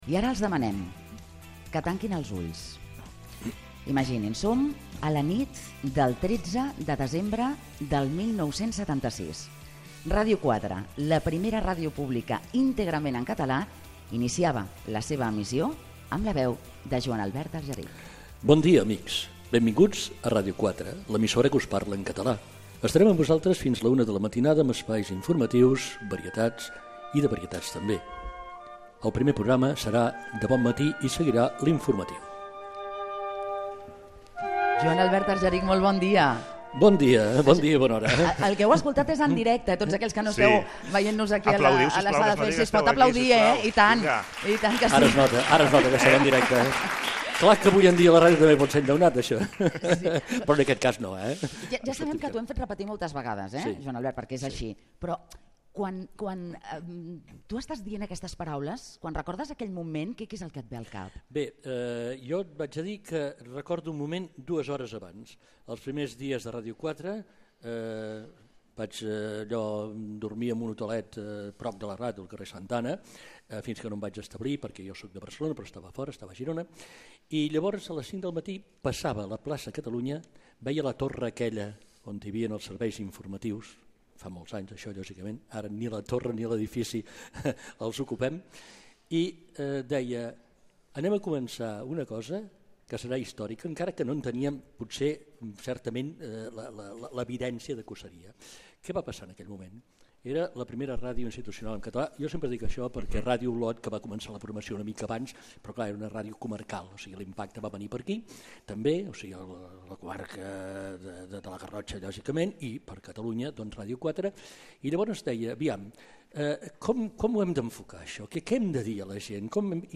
Programa especial amb motiu dels 40 anys de Ràdio 4 fet des del Foyer del Gran Teatre del Liceu.
Info-entreteniment